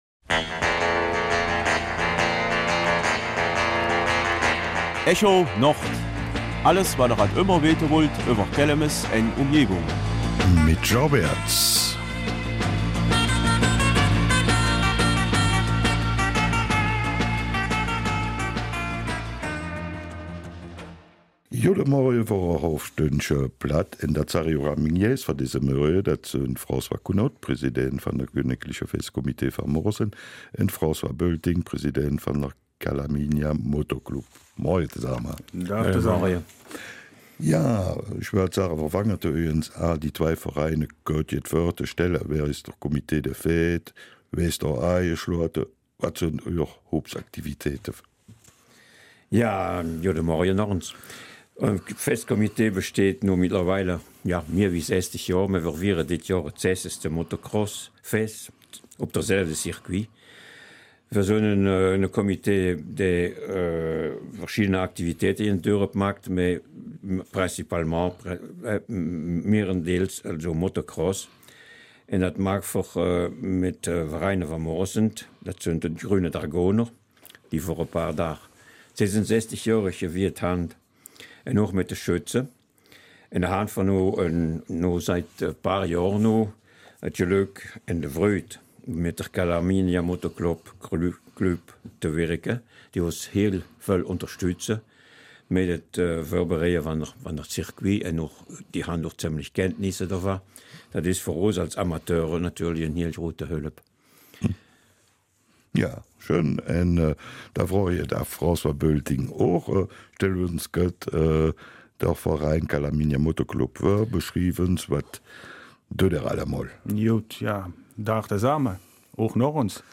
Kelmiser Mundart: Motocross in Moresnet